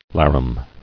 [lar·um]